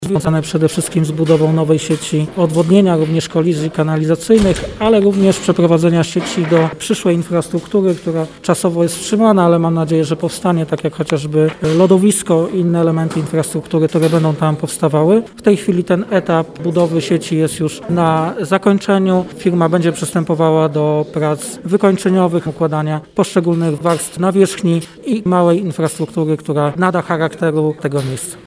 Powoli dobiega końca przebudowa parkingu przy Miejskim Ośrodku Sportu i Rekreacji w Stalowej Woli. Inwestycja miała się zakończyć w grudniu ubiegłego roku, ale dodatkowe prace spowodowały poślizg w realizacji. Mówi prezydent Stalowej Woli Lucjusz Nadbereżny: